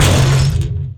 collision.mp3